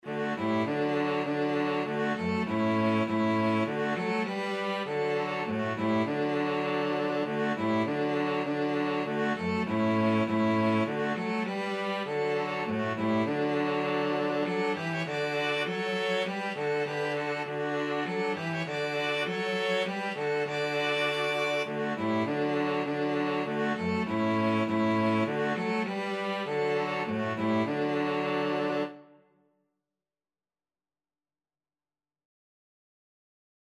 Free Sheet music for String trio
D major (Sounding Pitch) (View more D major Music for String trio )
Classical (View more Classical String trio Music)